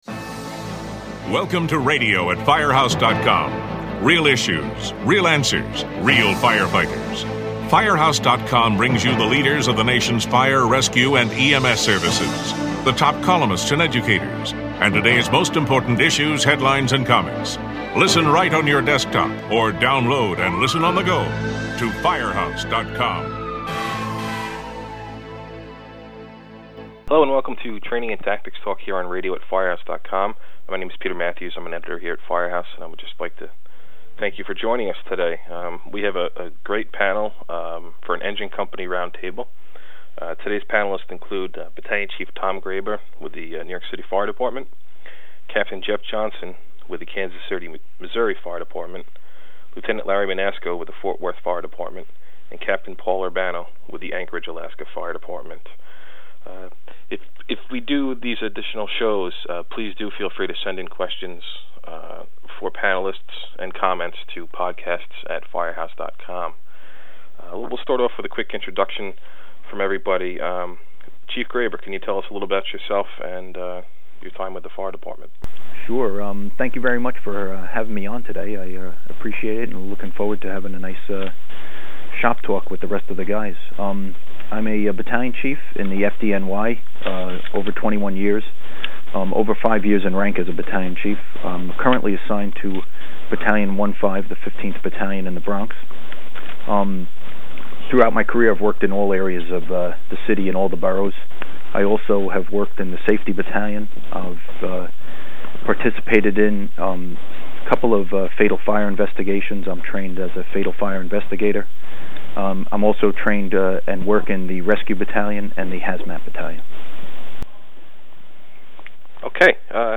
The roundtable discussion of Training & Tactics Talk ends with a scenario where the engine company is first-due and the next company is delayed.